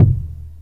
Boom-Bap Kick 83.wav